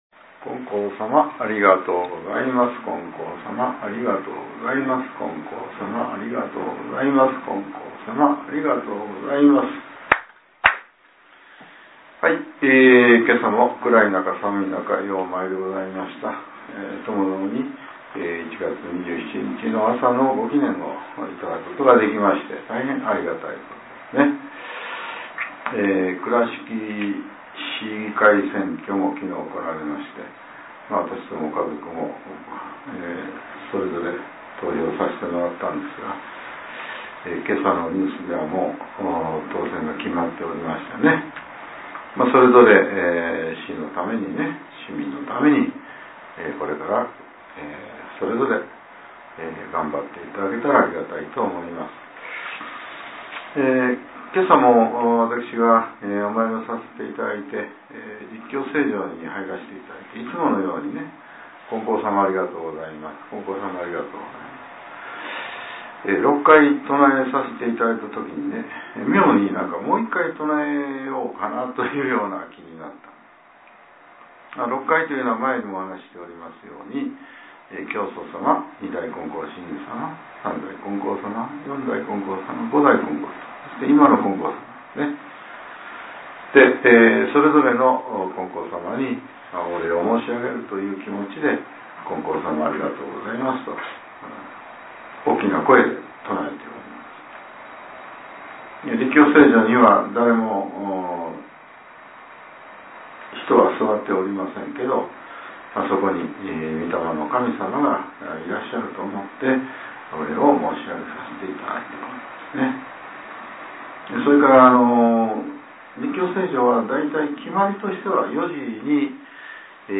令和７年１月２７日（朝）のお話が、音声ブログとして更新されています。